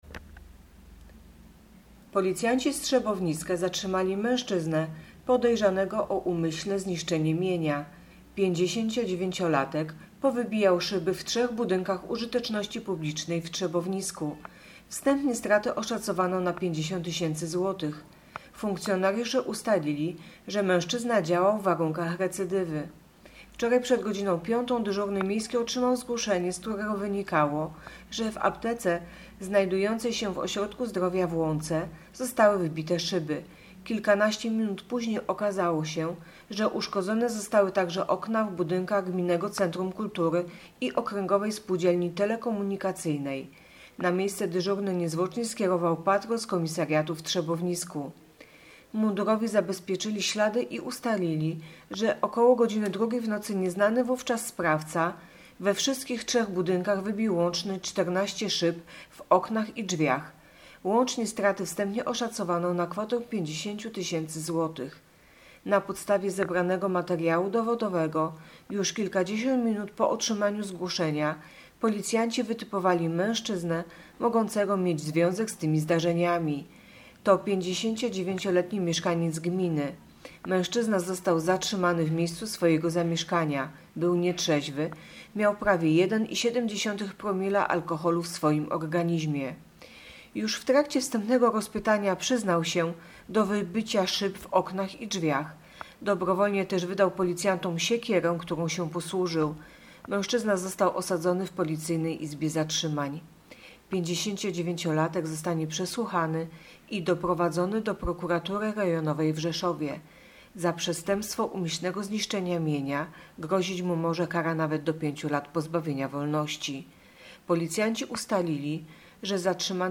Opis nagrania: Informacja pt. Policjanci z Trzebowniska zatrzymali wandala recydywistę.